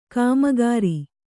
♪ kāmagāri